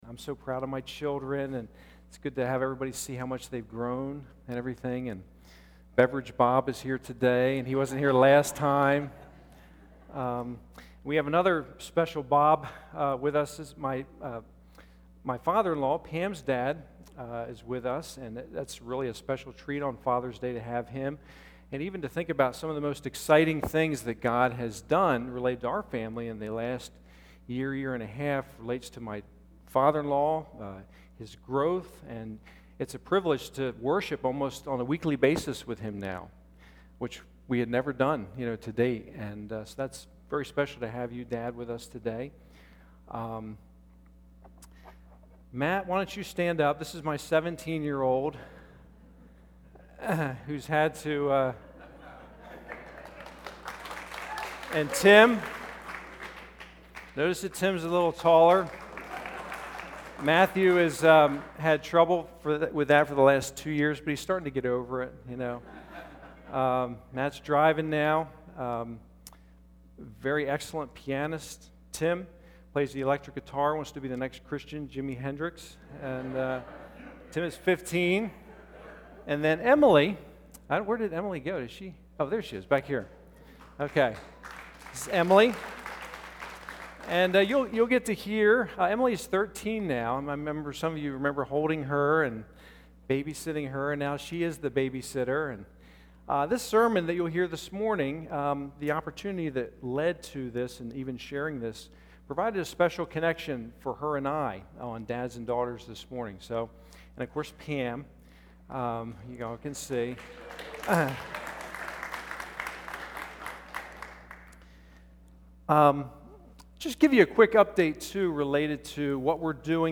Father's Day message 2009